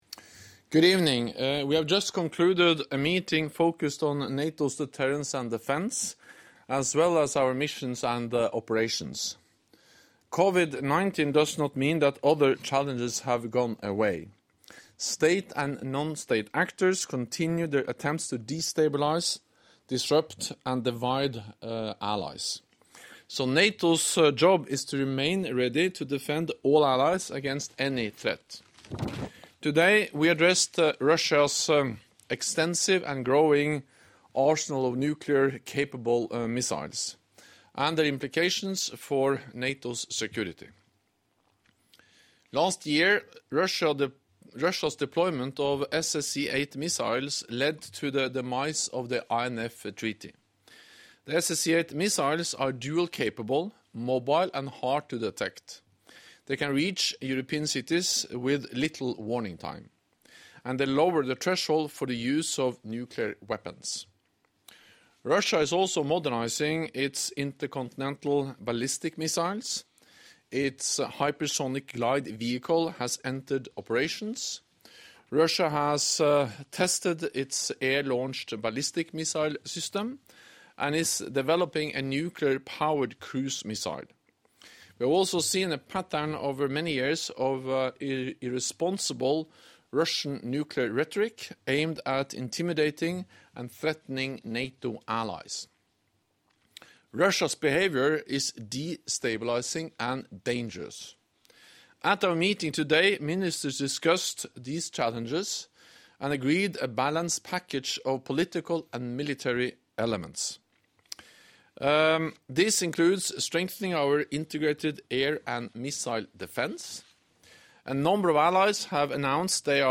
ENGLISH - Press conference by NATO Secretary General Jens Stoltenberg ahead of the meetings of NATO Defence Ministers
NATO Secretary General’s online pre-ministerial press conference